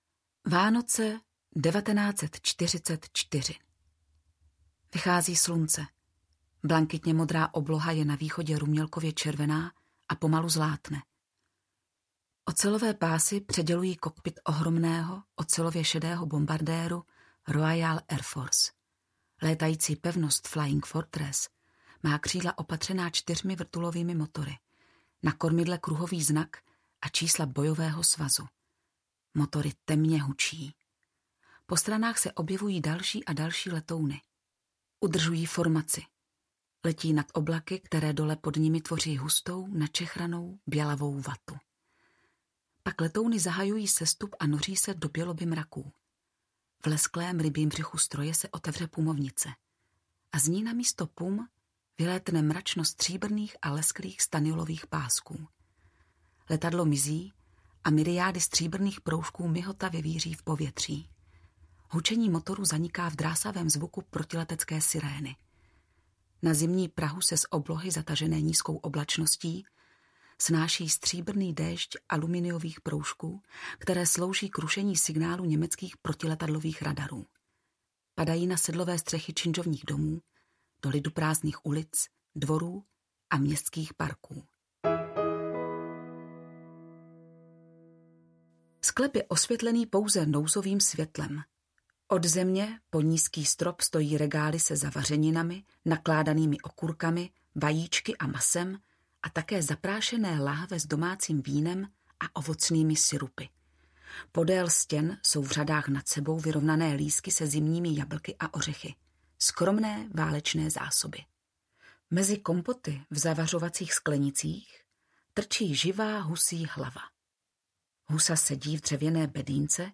Zahradnictví audiokniha
Ukázka z knihy